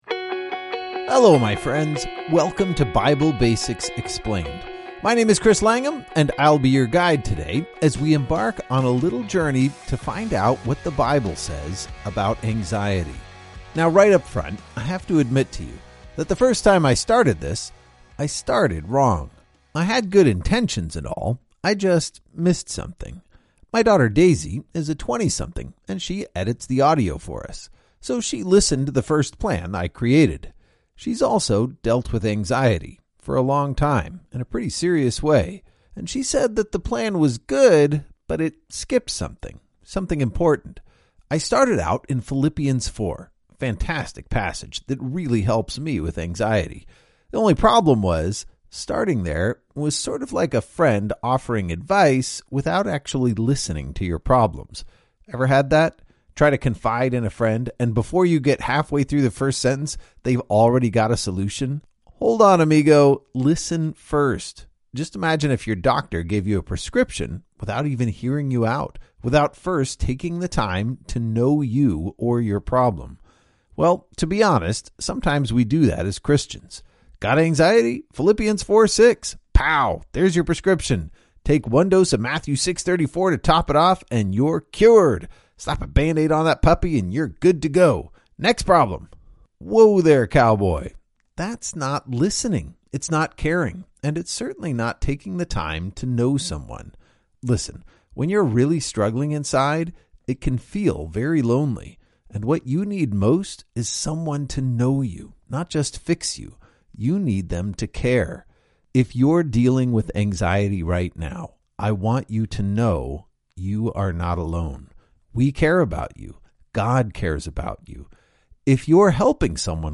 This devotional works best as an audio experience.